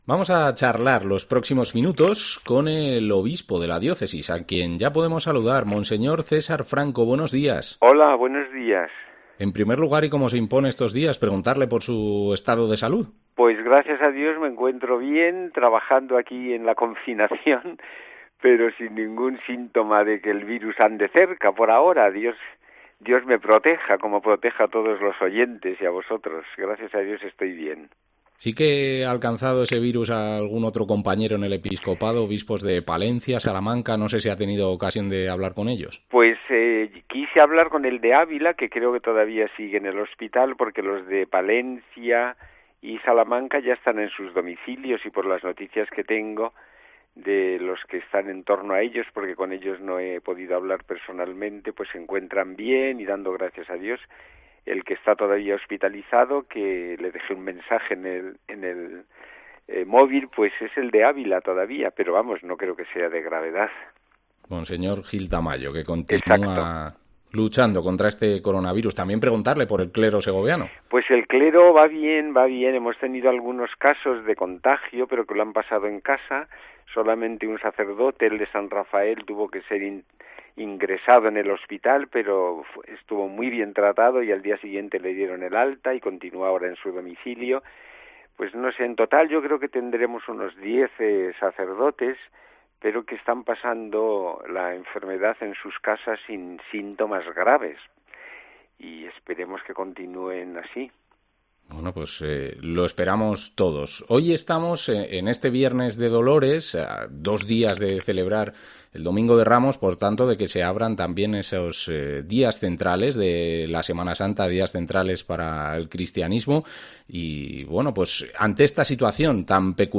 Entrevista a monseñor César Franco, obispo de Segovia